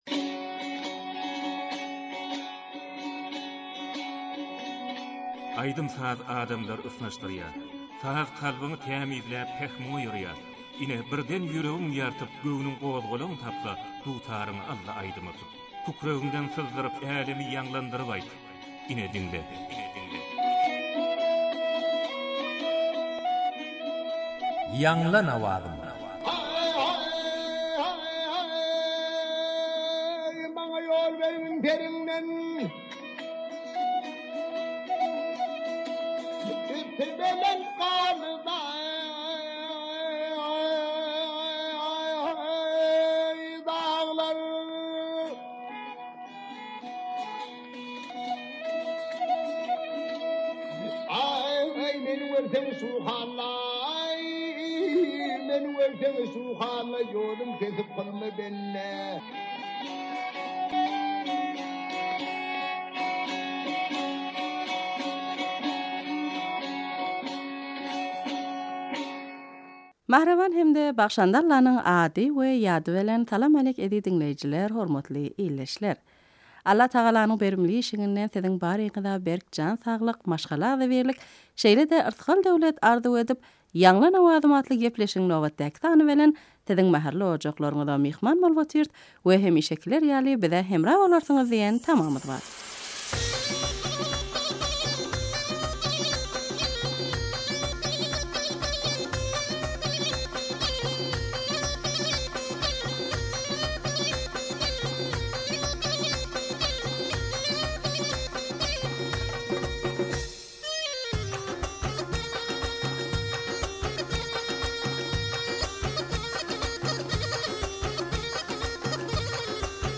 turkmen goşgy owaz aýdym